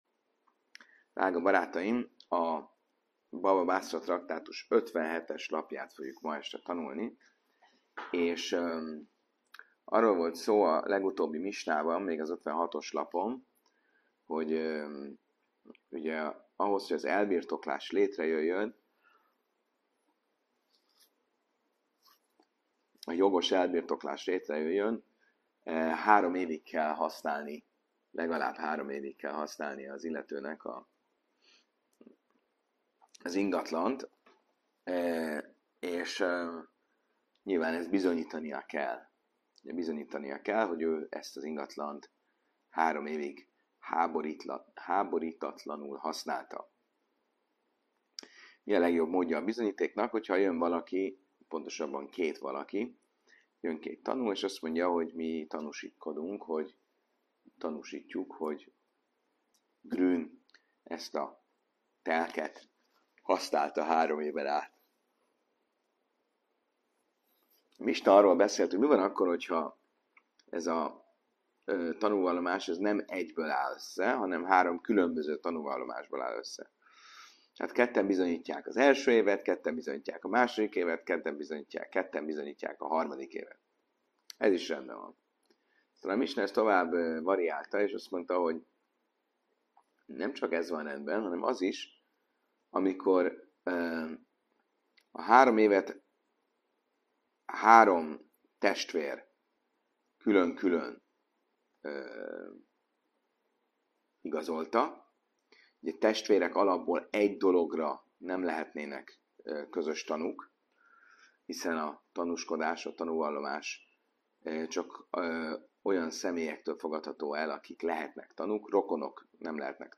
Helyszín: Óbudai Zsinagóga (1036. Budapest, Lajos u. 163.)